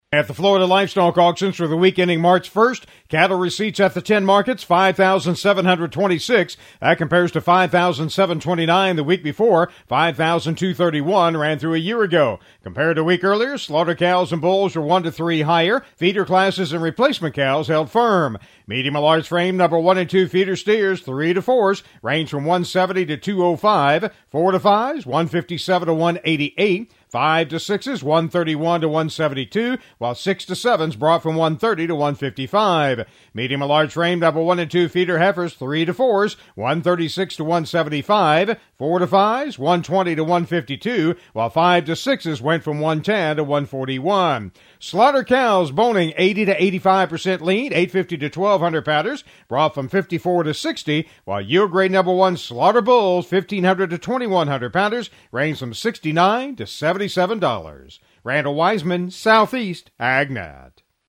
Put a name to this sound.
FL Livestock Market Report: